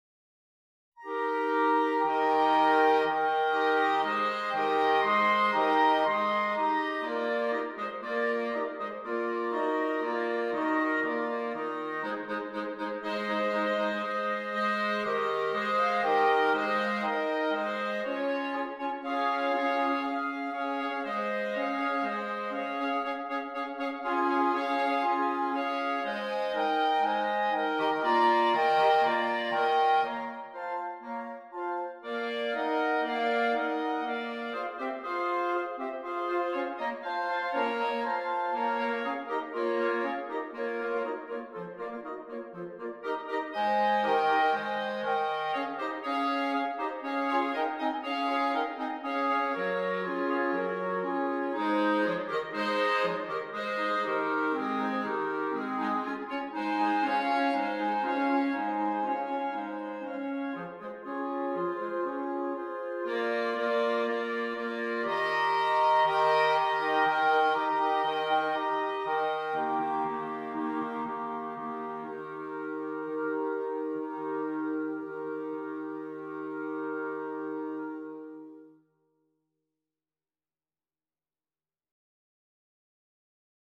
8 Clarinets